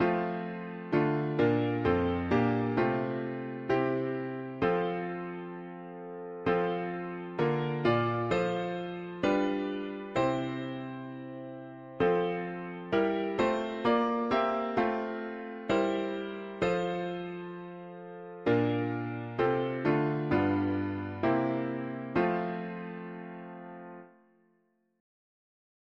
Tags english theist 4part